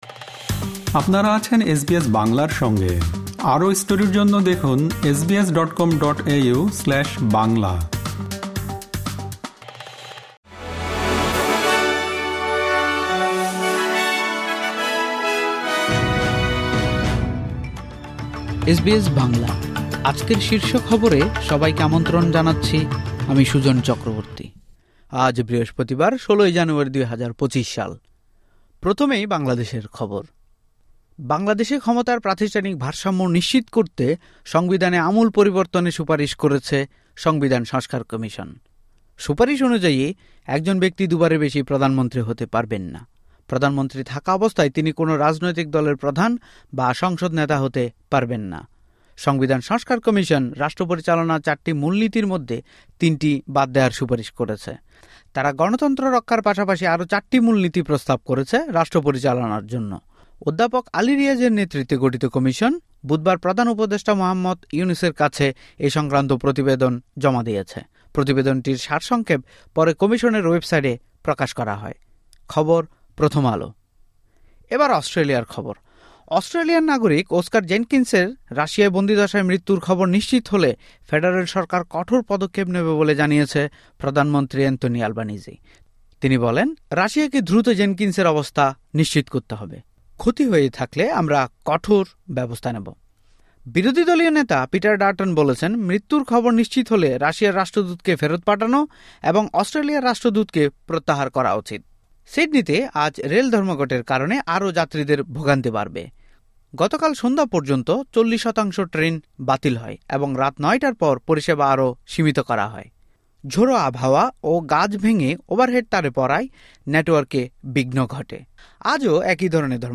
এসবিএস বাংলা শীর্ষ খবর: ১৬ জানুয়ারি, ২০২৫